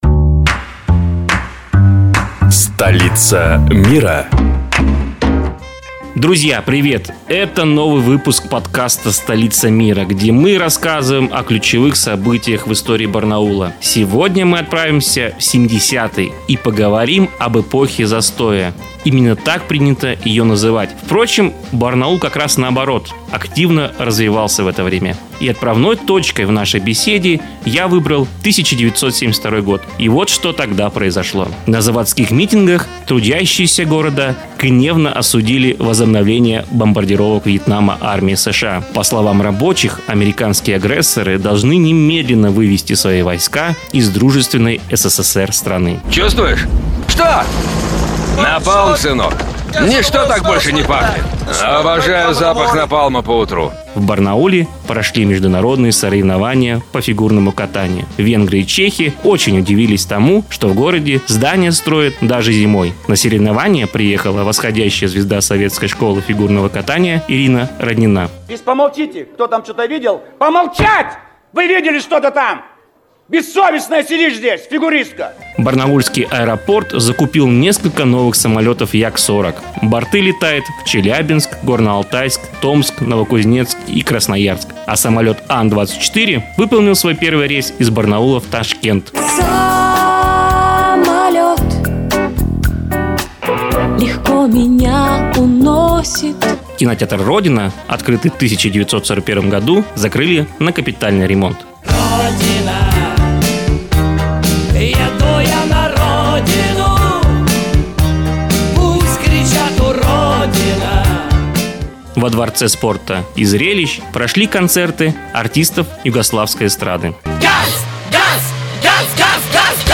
обсуждают визиты первых лиц страны в Барнаул